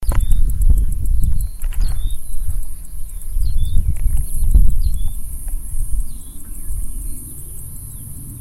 Data resource Xeno-canto - Bird sounds from around the world